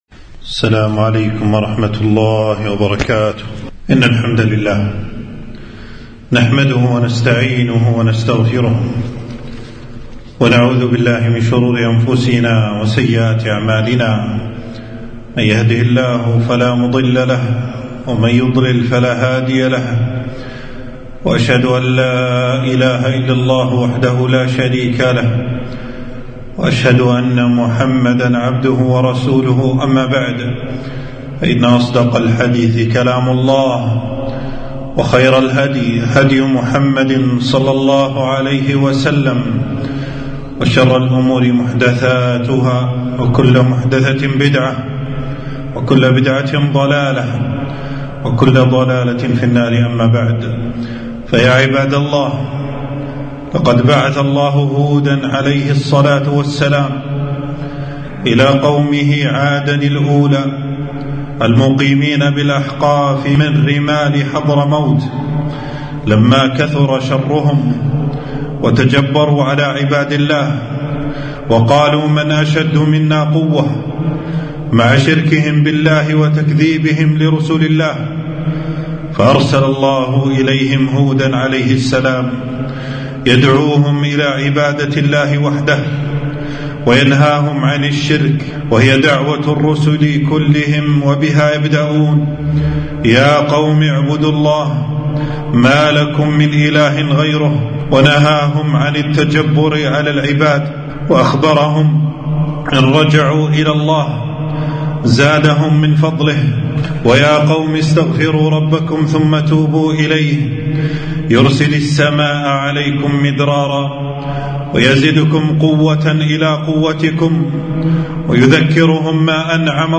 خطبة - قصة هود عليه السلام فوائد وعبر